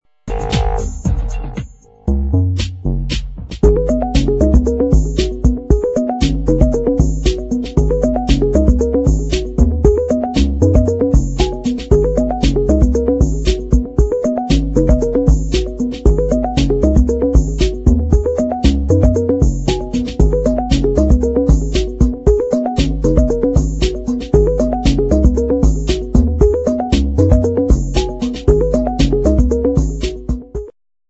exciting medium instr.